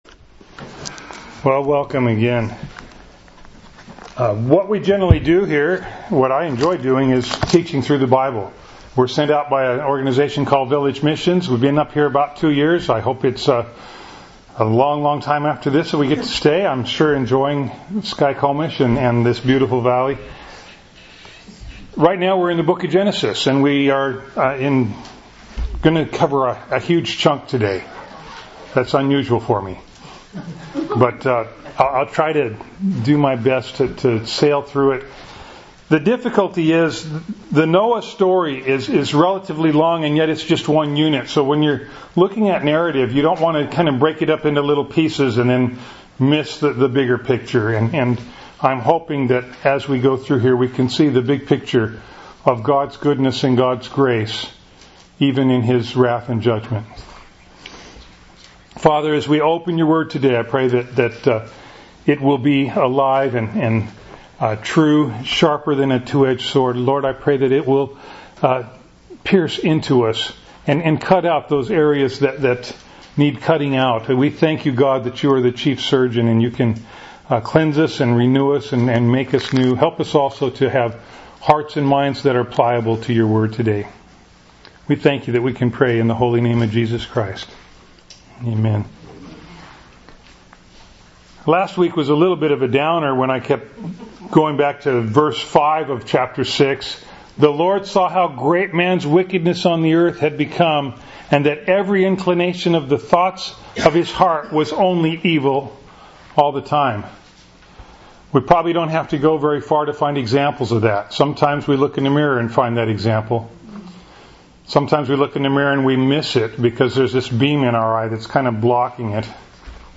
Bible Text: Genesis 6:9-9:17 | Preacher